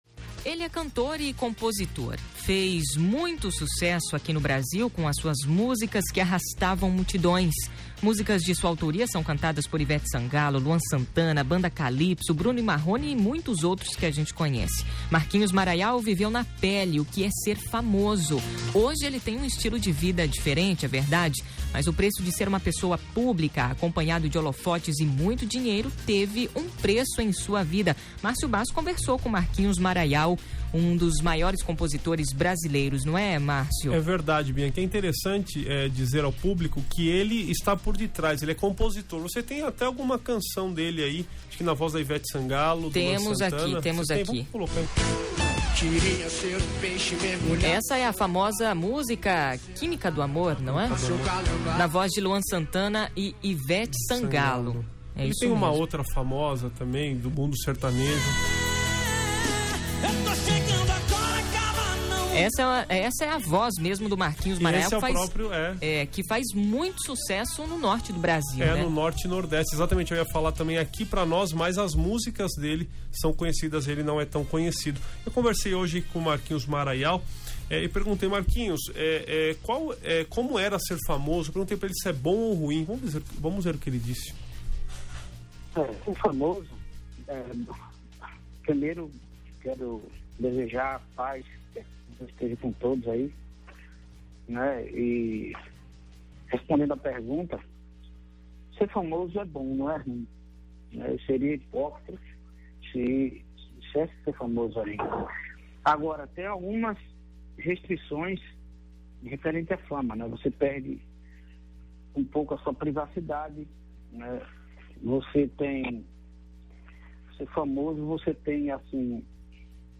Hoje ele tem um estilo de vida diferente, mas o preço de ser uma pessoa pública acompanhado de holofotes e muito dinheiro teve um preço em sua vida. O jornal Conexão Novo Tempo entrevistou Maraial, um dos maiores compositores do Brasil.